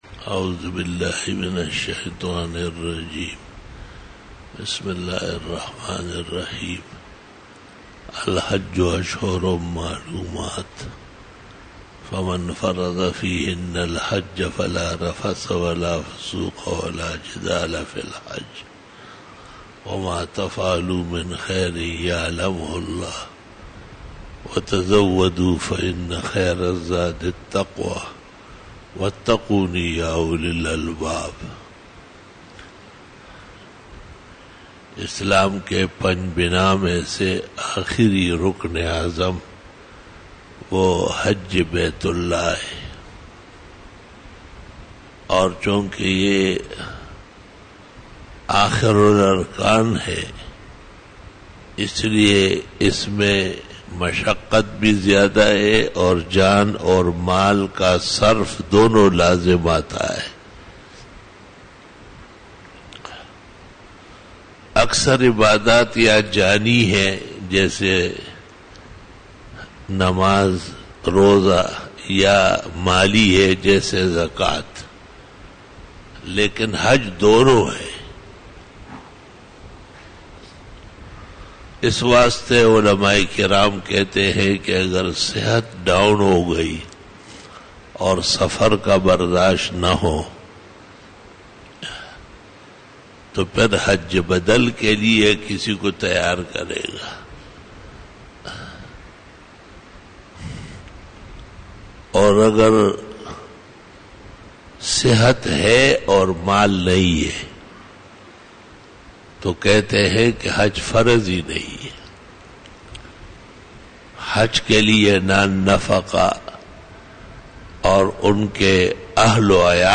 26 BAYAN E JUMA TUL MUBARAK (29 June 2018) (15 Shawwal 1439H)
Khitab-e-Jummah 2018